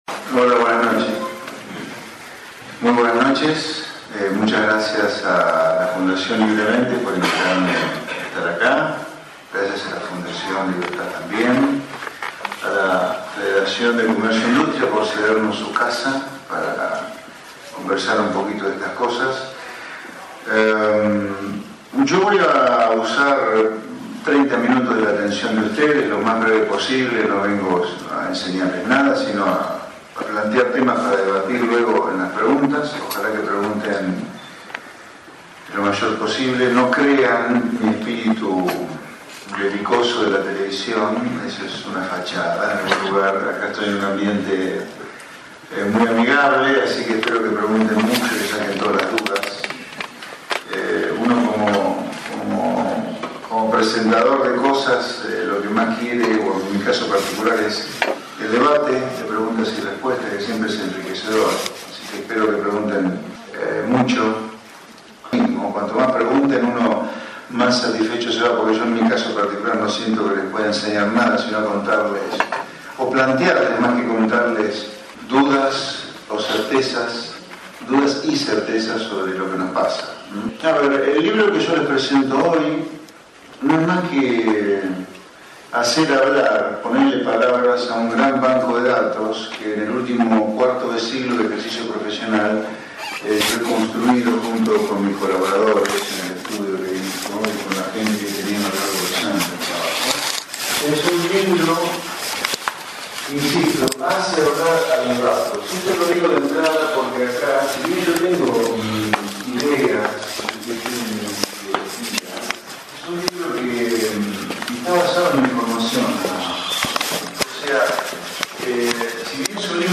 José Luis Espert Paso por La Federación de Comercio e Industria de San Nicolás
Muy verborrágico en manera desmedida, sabelotodo con muchísima razón de todo lo que cuenta y con mirada observadora de los hechos que cuenta nos responsabilizó a todos de todo lo que hemos perdido y del estancamiento por el cual pasamos.
Dejamos algunos pasajes de su  singular y autoritaria oratoria de este  profesional que desde su púlpito dejó callados a todos.